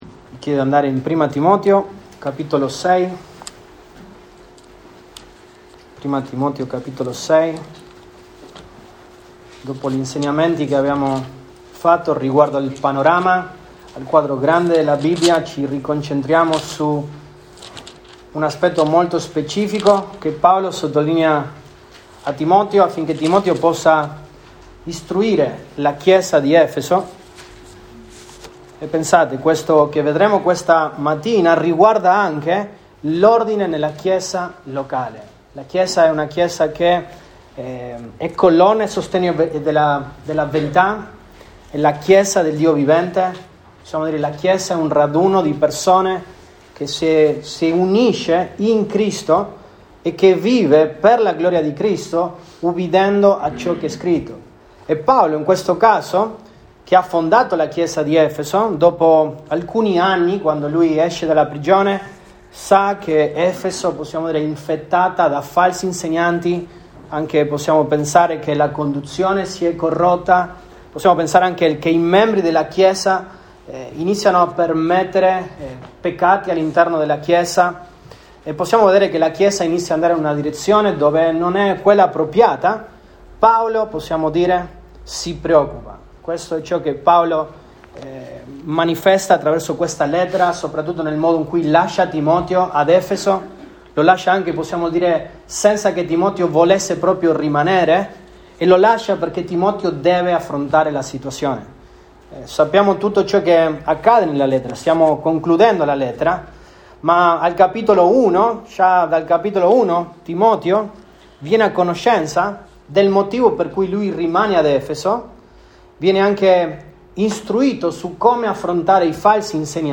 Sermoni